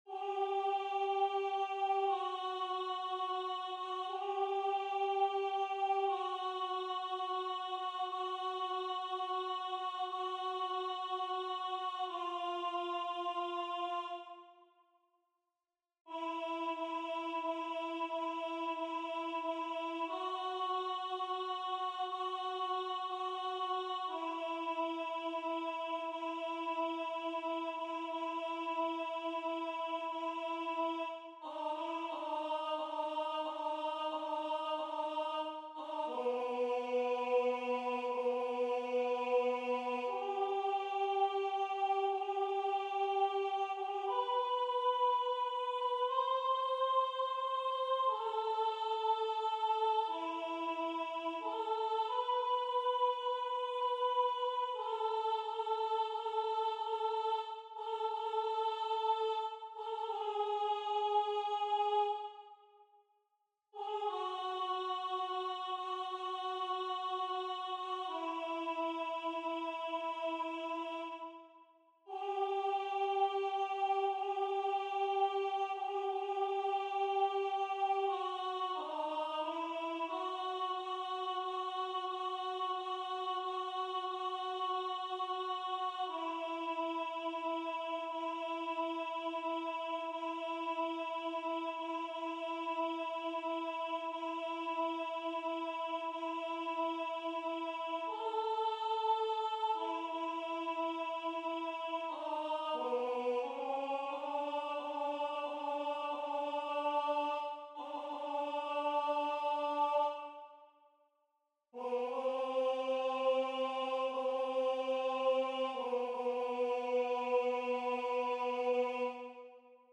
MP3 versions rendu voix synth.
Alto